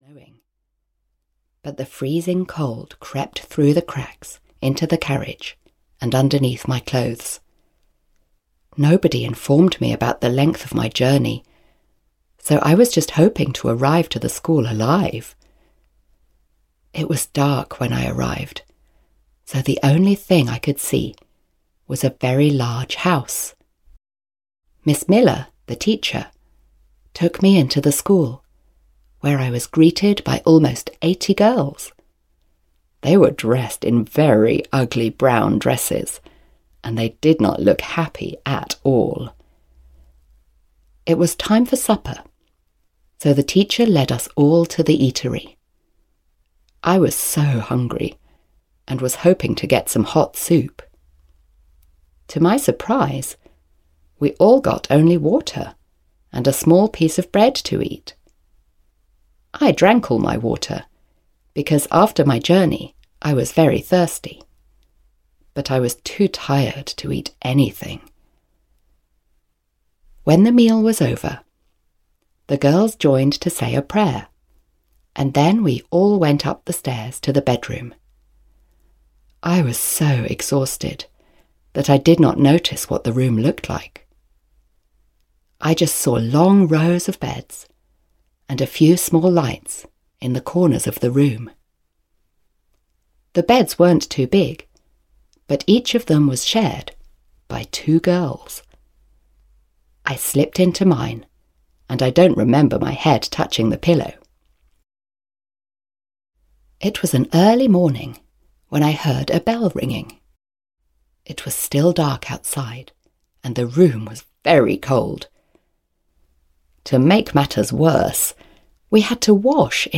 Jane Eyre (EN) audiokniha
Ukázka z knihy